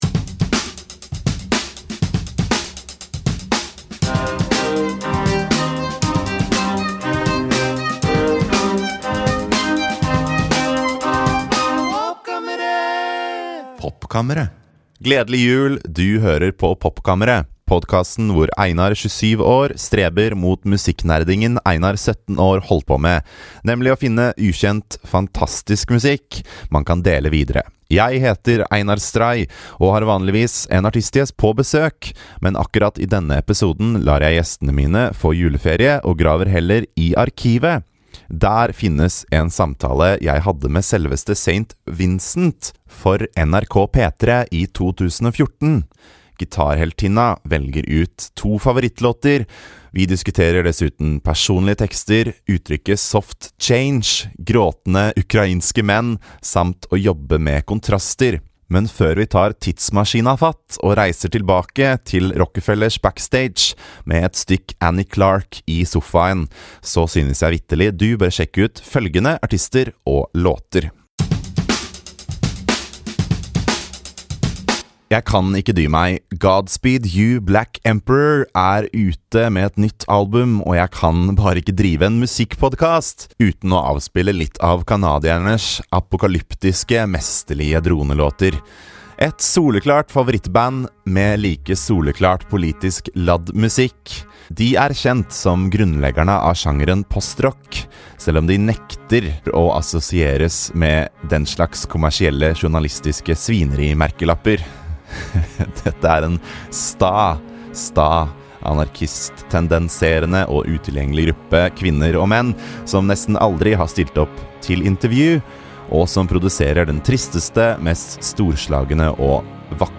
Der finnes en samtale jeg hadde med selveste St. Vincent for NRK P3 i 2014. Heltinne Annie Clark velger ut to favorittlåter.